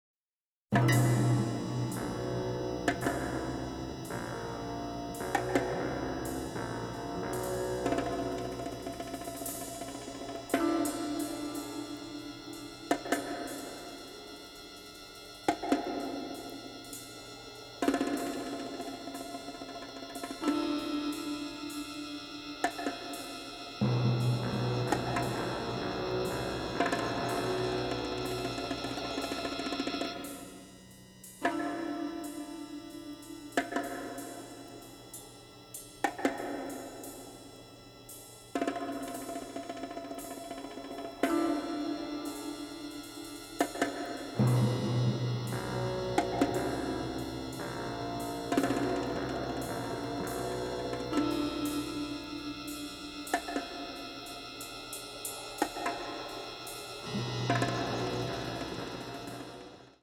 western score